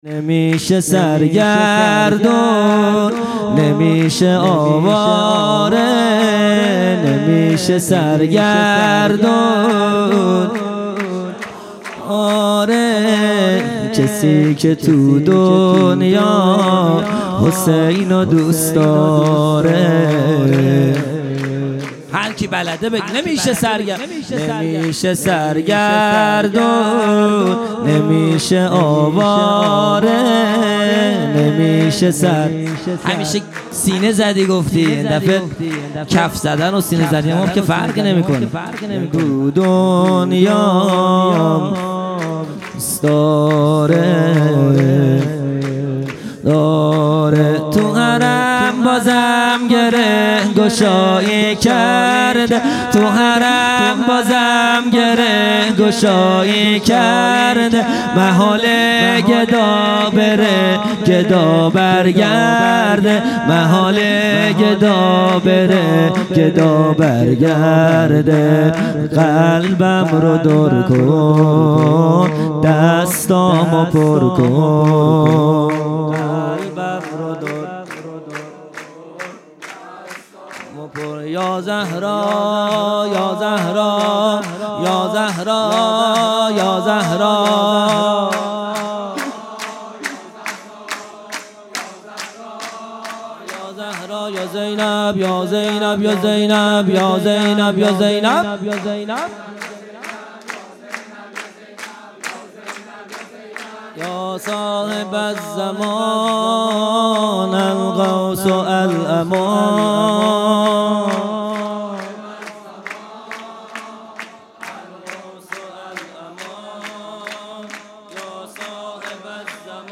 خیمه گاه - هیئت بچه های فاطمه (س) - سرود پایانی | نمیشه سرگردون، نمیشه آواره
جلسۀ هفتگی (به مناسبت ولادت امام هادی(ع))